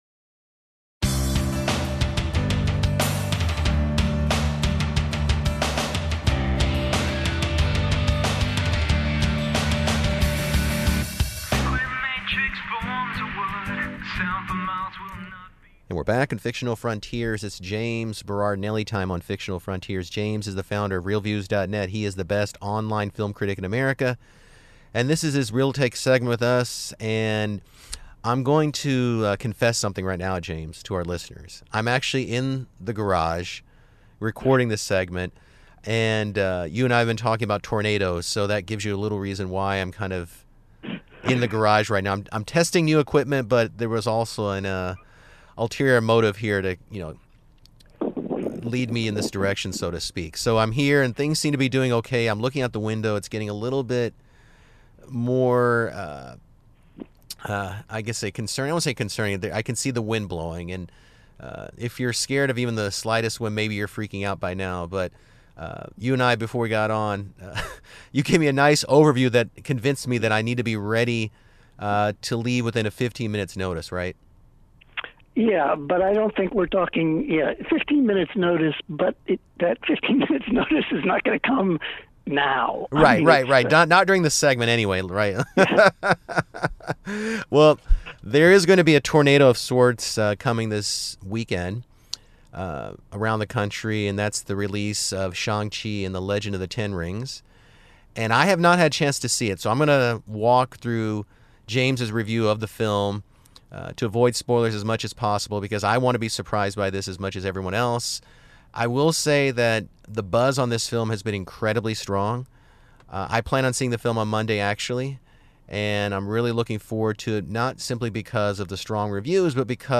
The nation's only serious weekly radio discussion about today's popular culture.